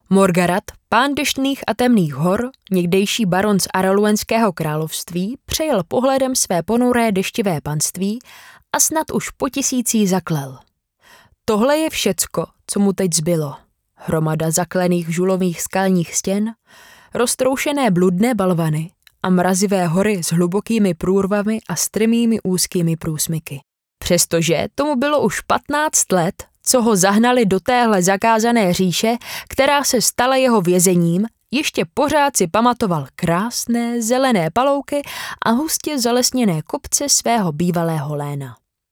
Ženský reklamní voiceover do jedné minuty
Natáčení probíhá v profesiálním dabingovém studiu.